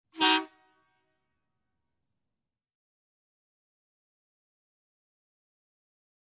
Truck Semi Horn; Single Blast